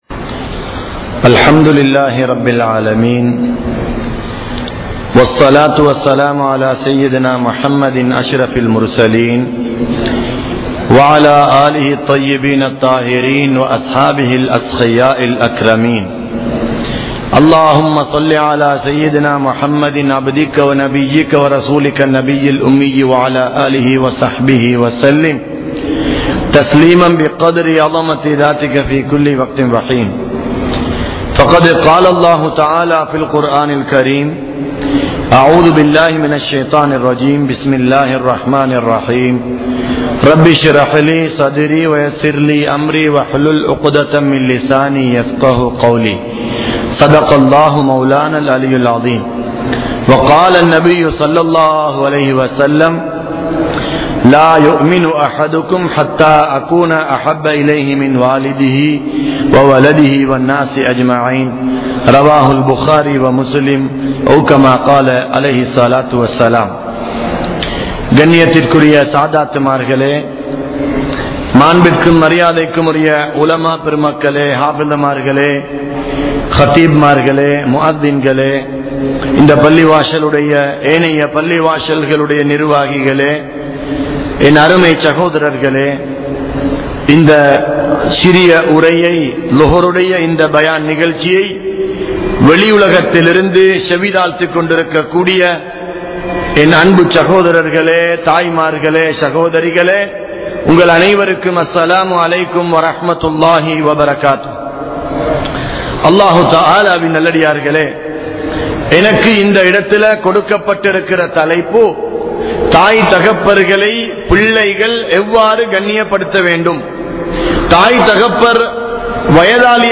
Respecting Parents (பெற்றோர்களை மதியுங்கள்) | Audio Bayans | All Ceylon Muslim Youth Community | Addalaichenai